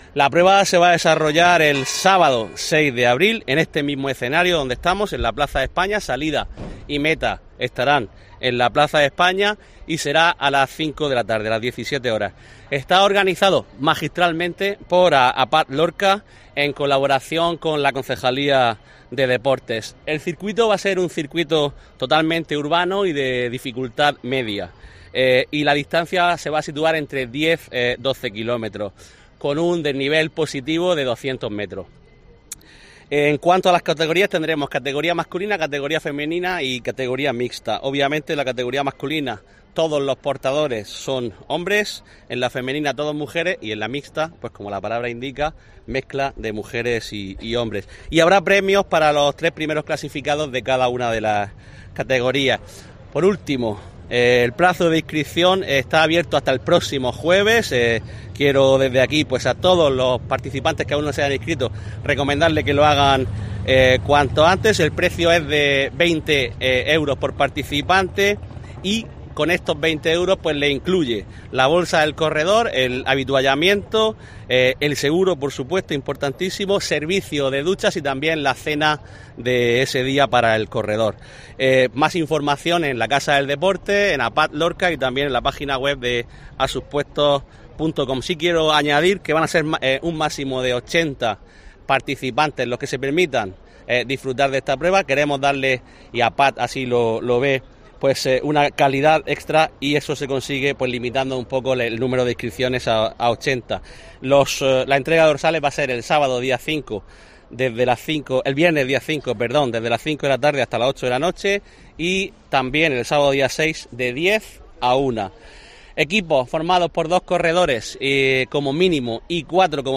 Juan Miguel Bayonas, edi deportes Ayuntamiento de Lorca